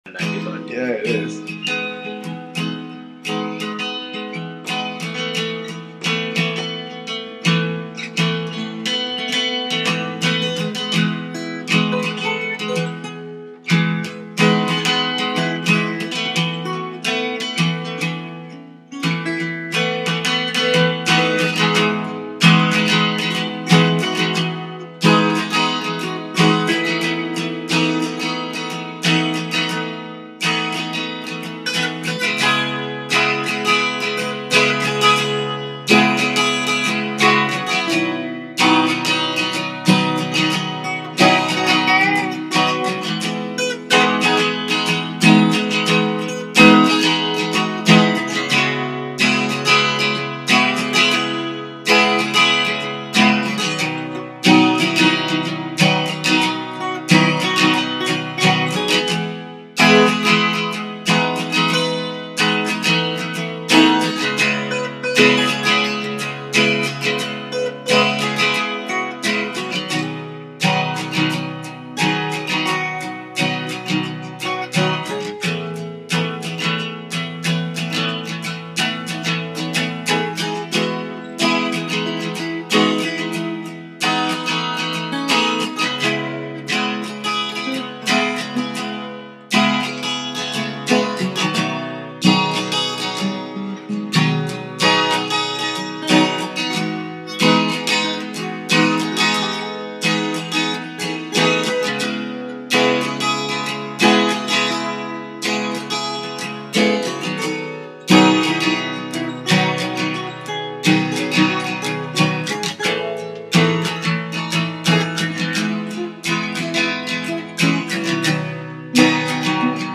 Random jam whilst in Vancouver
Rhythm Guitar
Lead Guitar